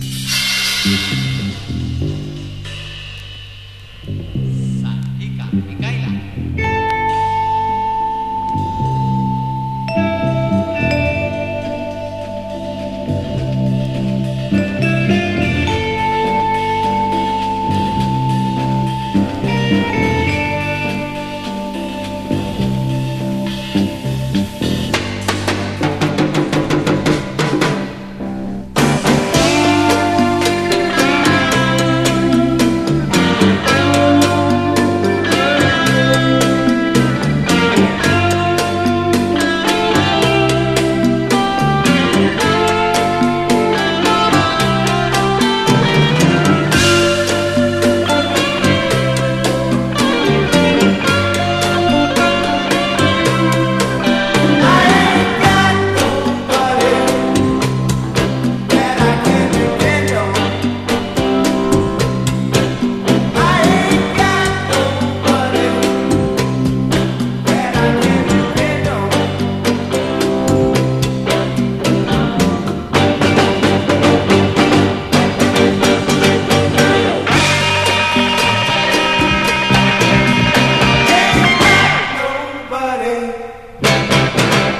ROCK / 70'S
魂を振り絞るような渾身のギター・フレーズの掛け合いに呪術的な歌唱も交えてスピリチュアルに昇り詰めていく